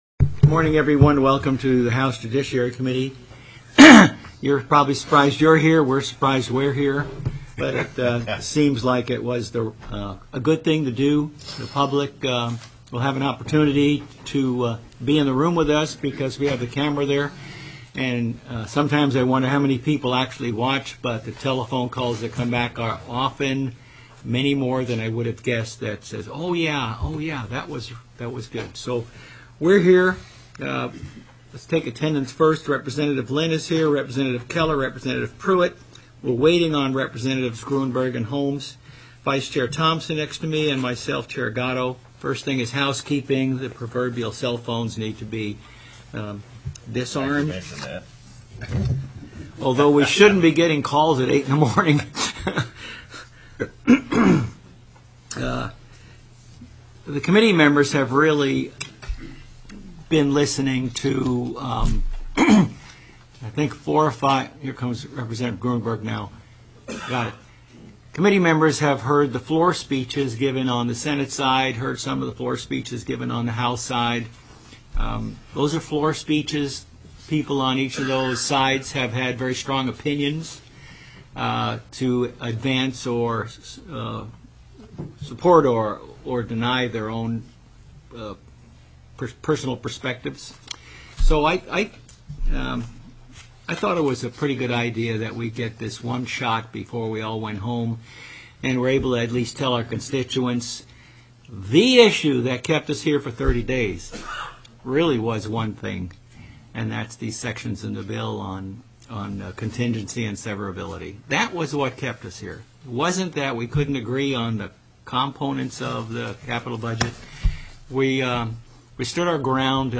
ALASKA STATE LEGISLATURE HOUSE JUDICIARY STANDING COMMITTEE Anchorage, Alaska May 11, 2011 8:06 a.m.